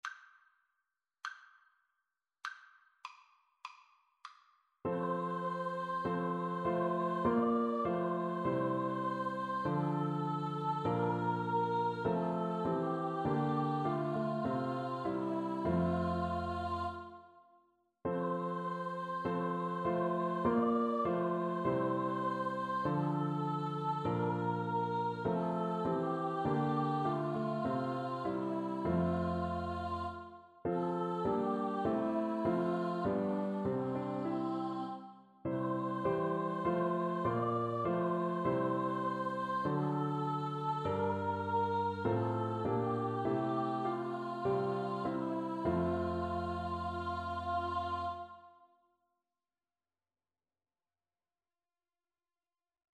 Free Sheet music for Choir (SATB)
4/4 (View more 4/4 Music)
F major (Sounding Pitch) (View more F major Music for Choir )
Christmas (View more Christmas Choir Music)